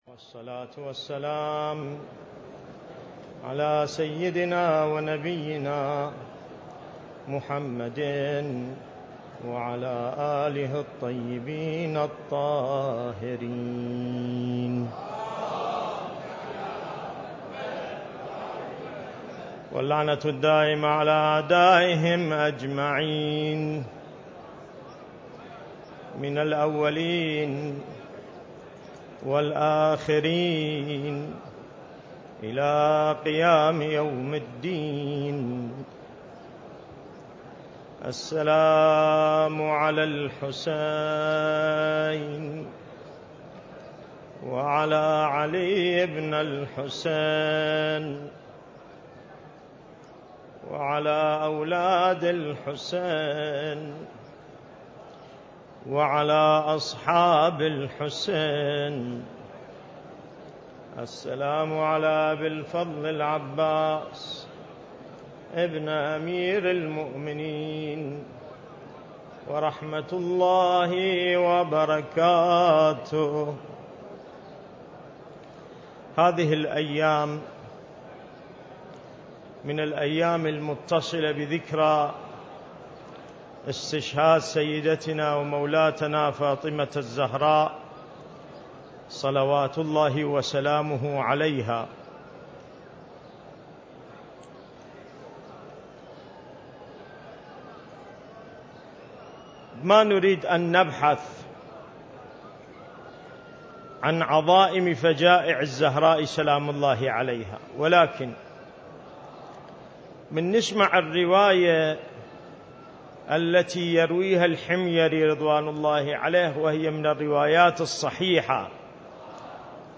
المكان: العتبة الحسينية المقدسة